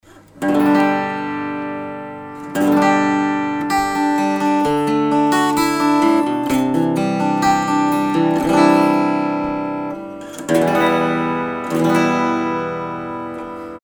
Test Audio Vergleich Sattel Knochen vs Graph Tech
Test Vergleich: Mikrofon: Shure KSM 141 Abstand 30cm, direkt gerichtet auf 12. Bund Gretsch 5420T Die Saiten sind für den Test nicht erneuert worden.